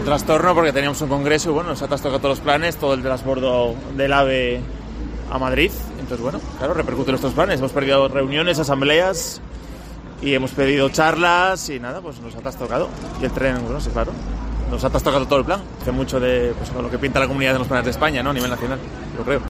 Pasajeros afectados por la avería del AVE Oviedo-Madrid